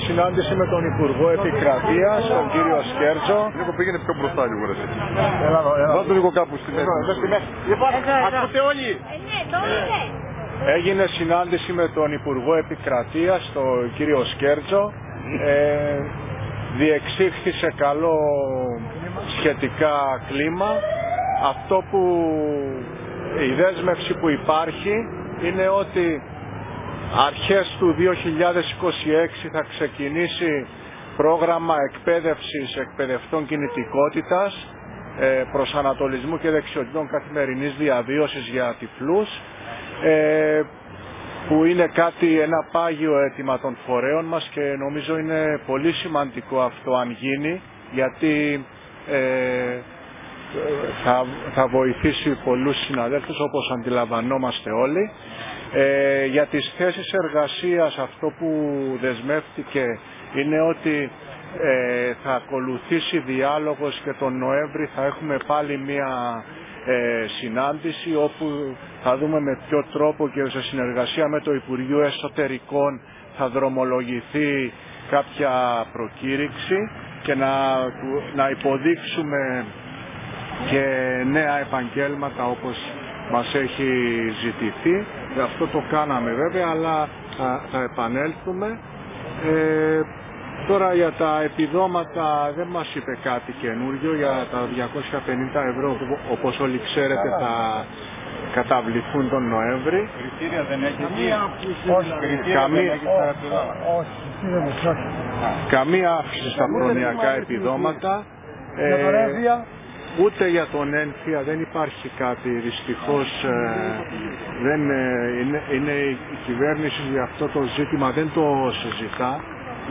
το δεύτερο είναι η ενημέρωση